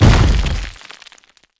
fall_heavy.wav